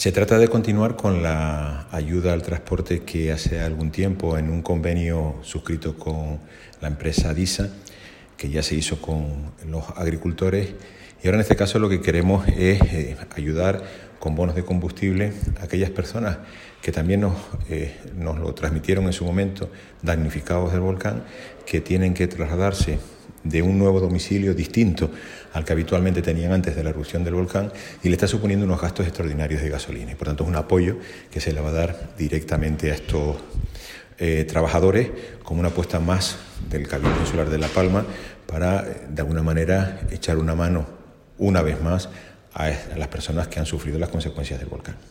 Declaraciones audio Carlos Cabrera_1.mp3